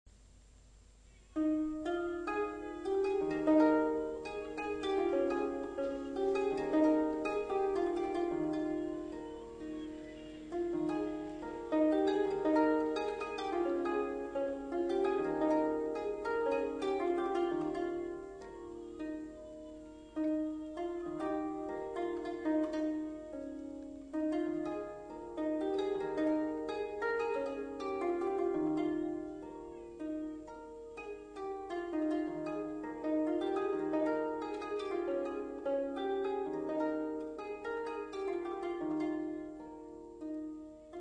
HARPE